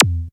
bot_jump.wav